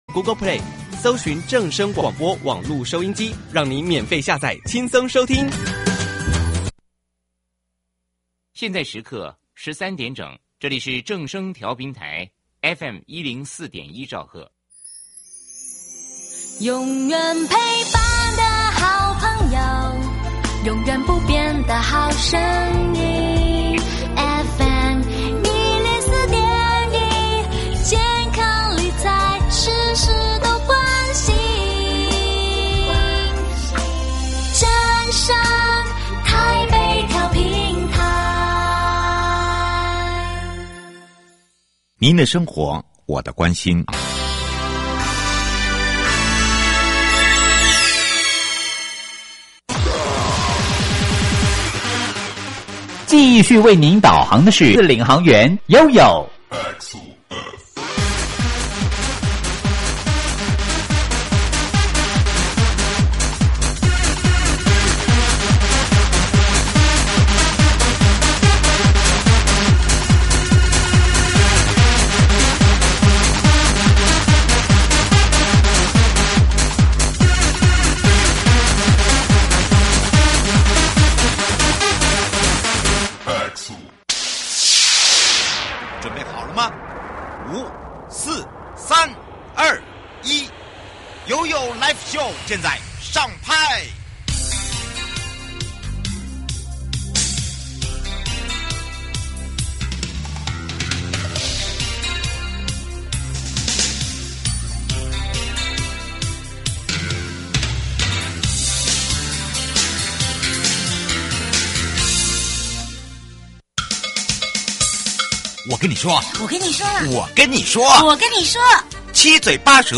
受訪者： 營建你我他 快樂平安行~七嘴八舌講清楚~樂活街道自在同行!(二) 以人為本 推動通行環境再升級 臺中